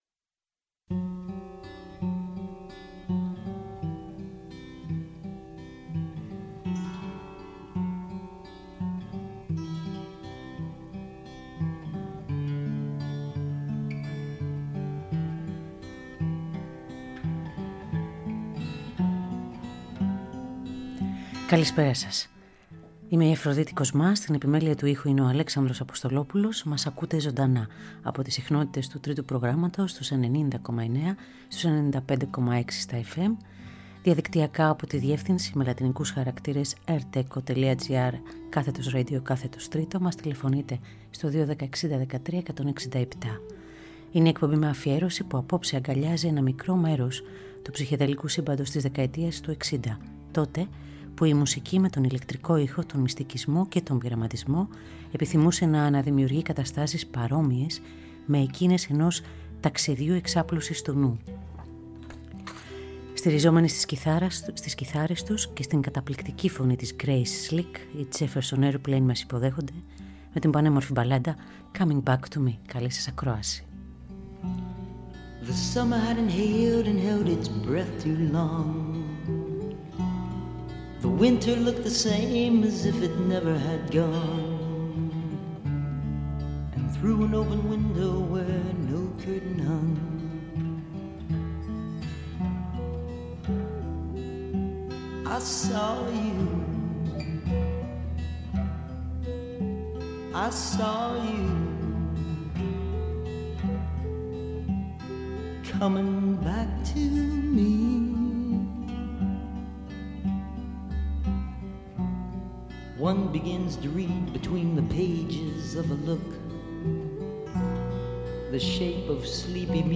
Ζωντανά από το στούντιο του Τρίτου Προγράμματος.